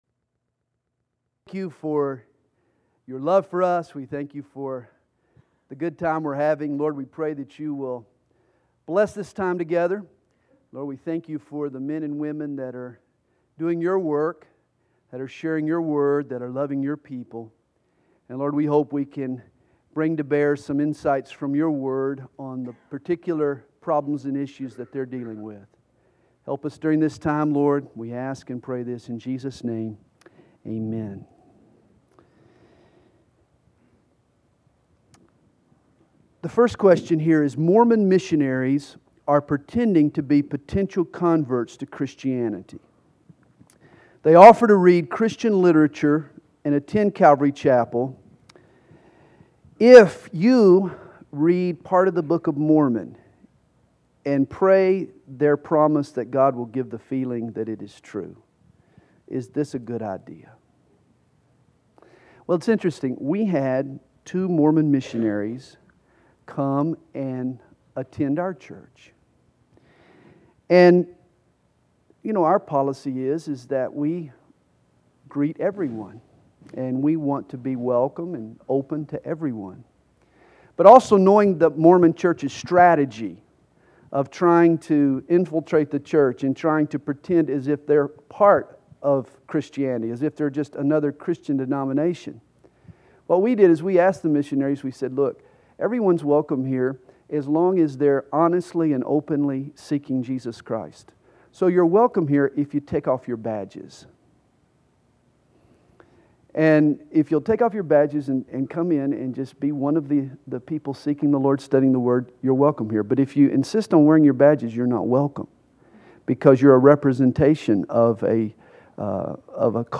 Session 7 – Q & A
Conference: Pastors & Leaders